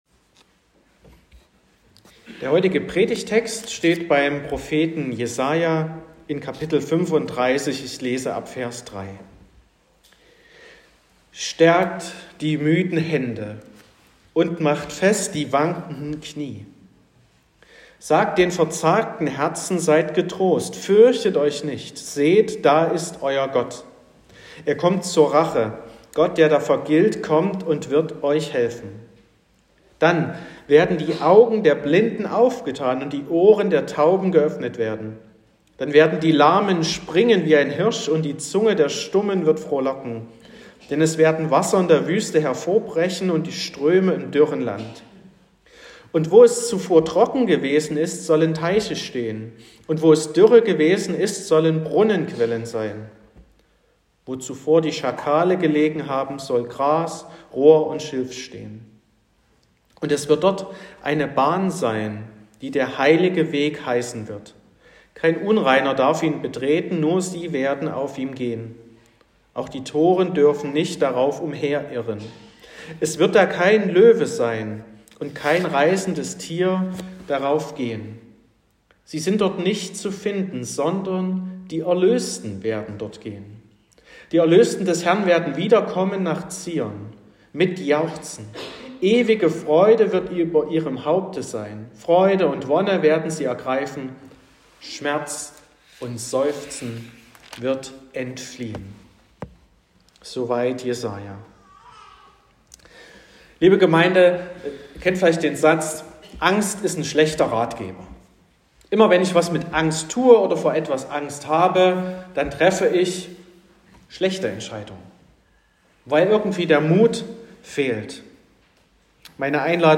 08.12.2024 – Gottesdienst
Predigt (Audio): 2024-12-08_Das_aengstliche_Herz.m4a (8,9 MB)